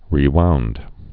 (rē-wound)